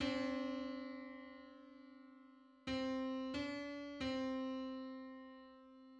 Just: 35/32 = 155.14 cents.
Public domain Public domain false false This media depicts a musical interval outside of a specific musical context.
Thirty-fifth_harmonic_on_C.mid.mp3